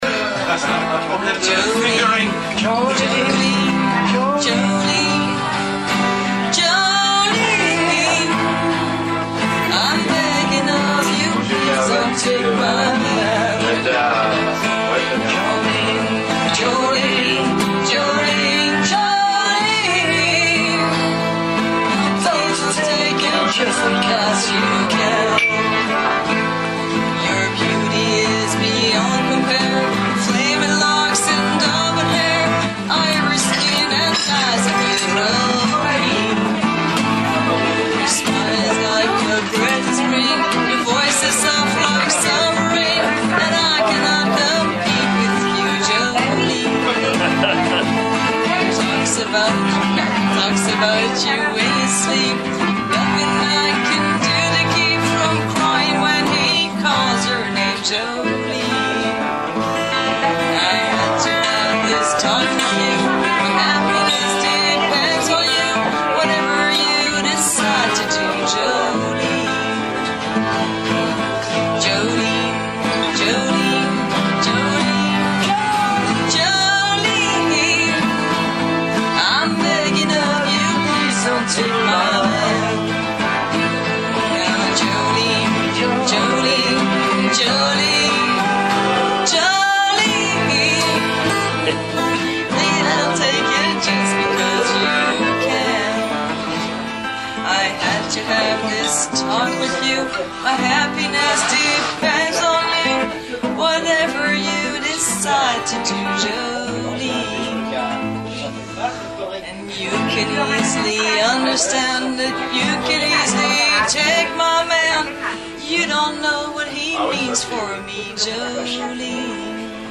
un enregistrement durant une session de l'hivers dernier